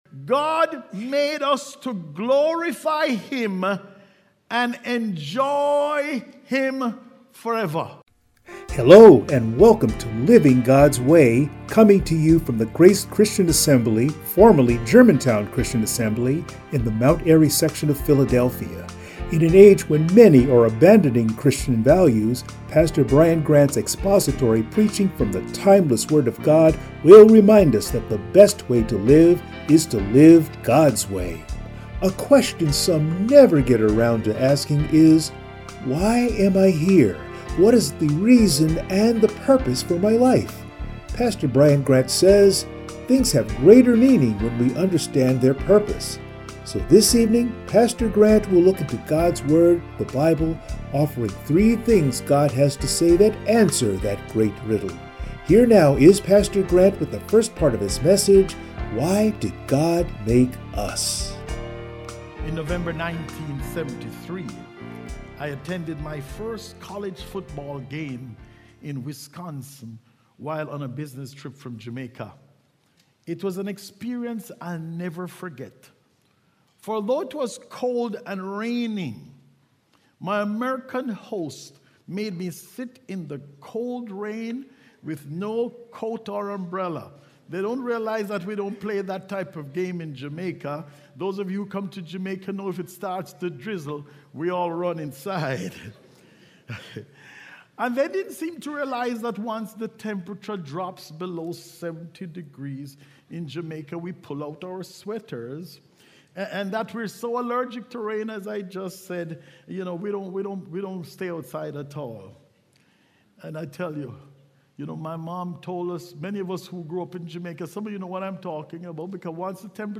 Matthew 28:18-20 Service Type: Sunday Morning “WHY AM I HERE?